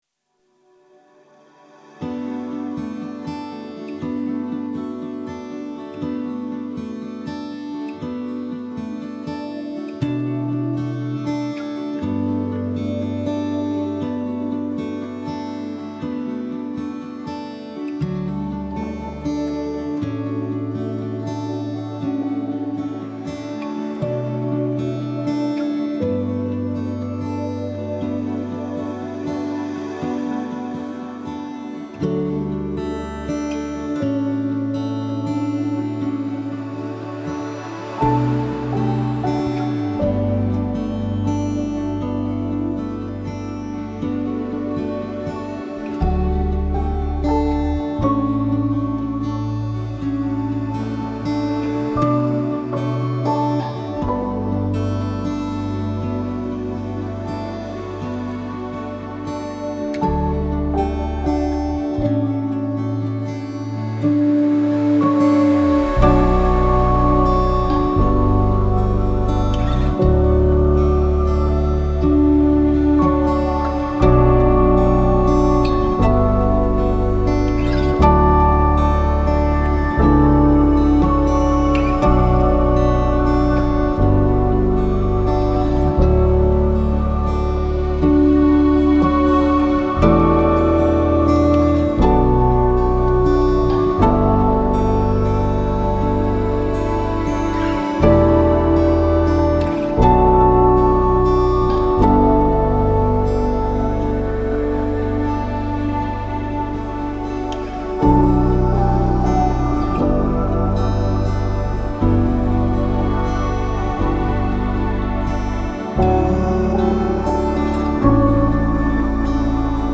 موسیقی کنار تو
آرامش بخش , الهام‌بخش , پیانو , گیتار , موسیقی بی کلام